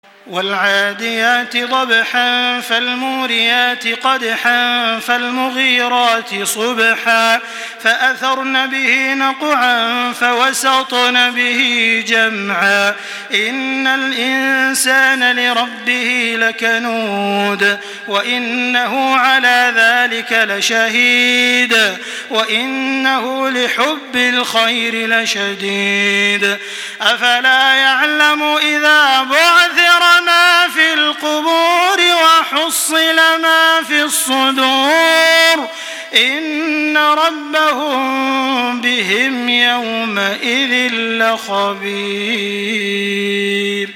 تراويح الحرم المكي 1425
مرتل